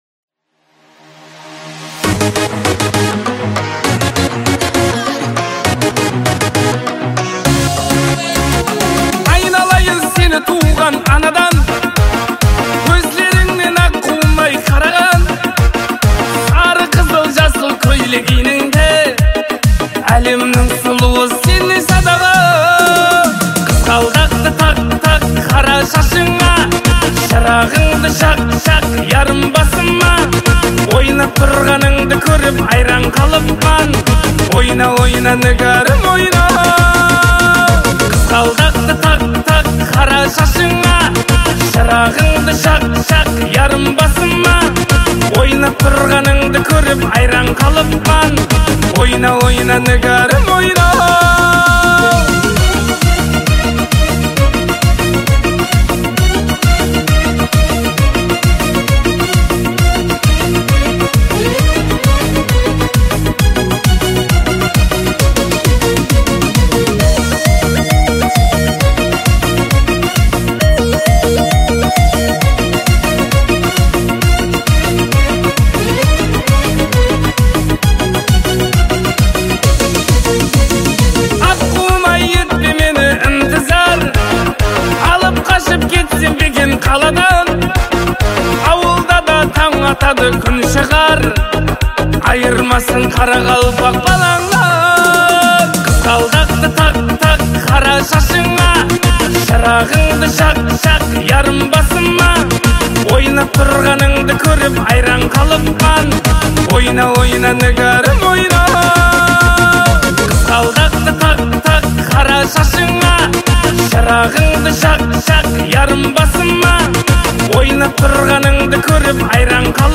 Жанр: Казахские песни